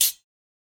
Perc (10).wav